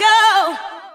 ремикс/рефреш